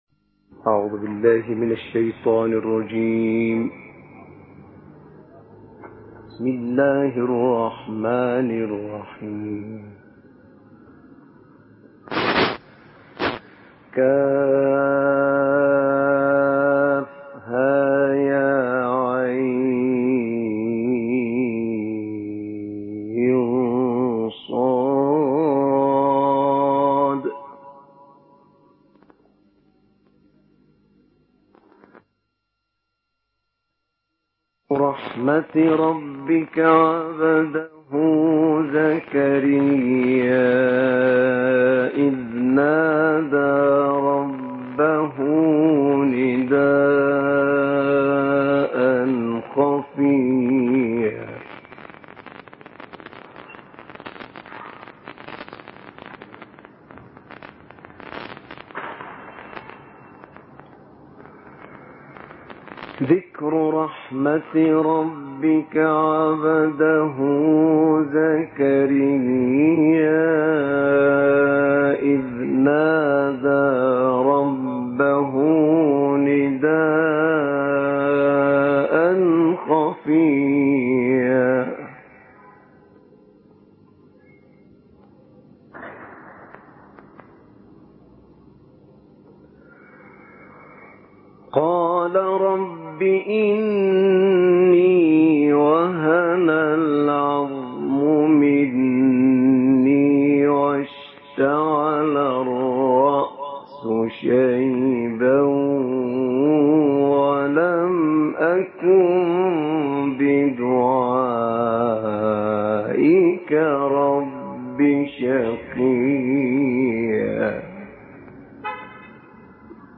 تلاوت آیاتی از سوره مریم توسط استاد شیخ احمد نعینع